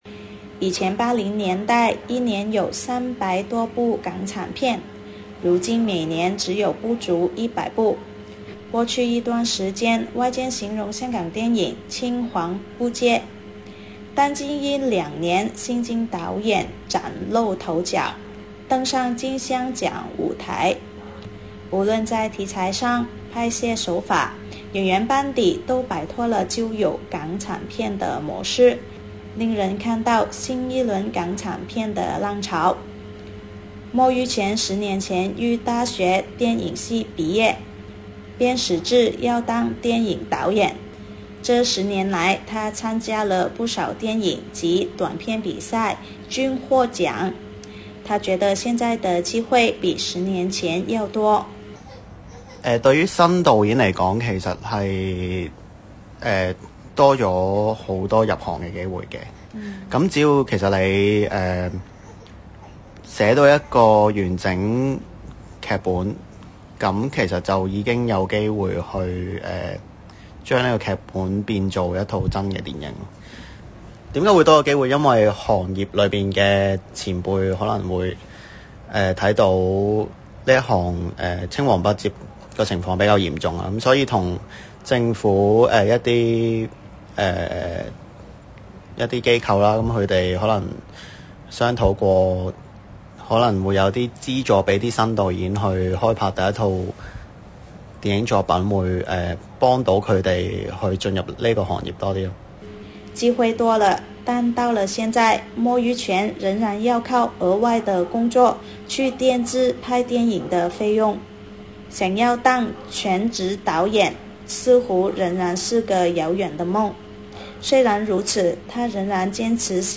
hong_kong_report_the_rejuvenated_film_industry_in_hk.mp3